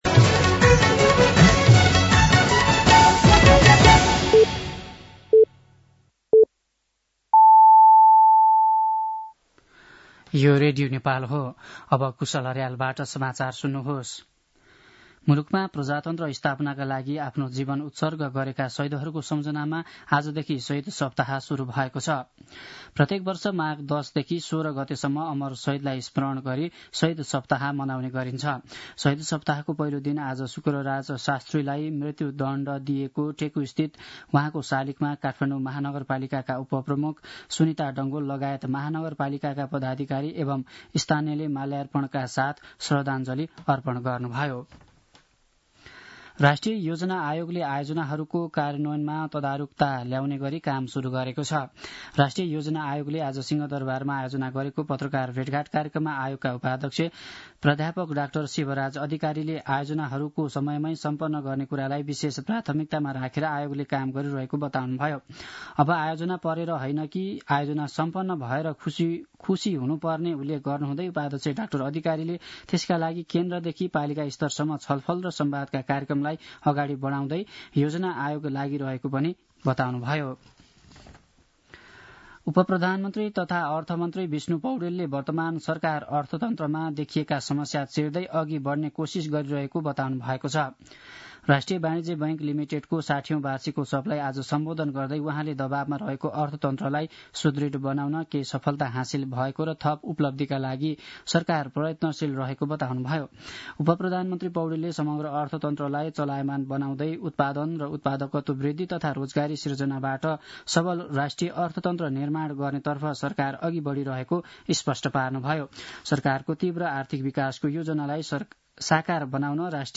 साँझ ५ बजेको नेपाली समाचार : ११ माघ , २०८१